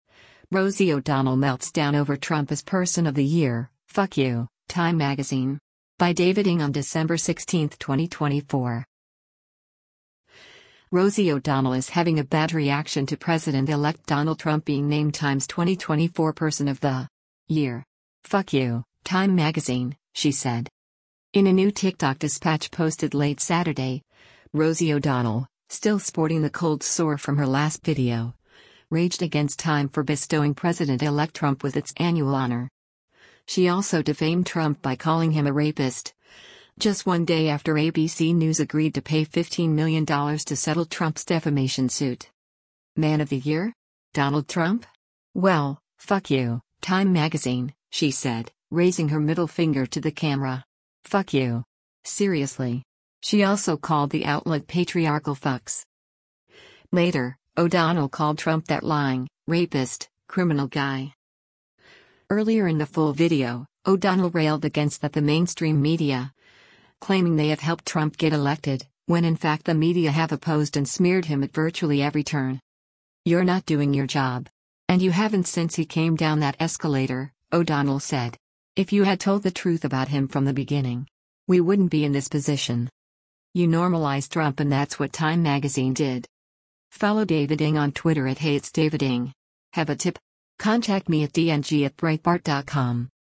In a new TikTok dispatch posted late Saturday, Rosie O’Donnell, still sporting the cold sore from her last video, raged against Time for bestowing President-elect Trump with its annual honor.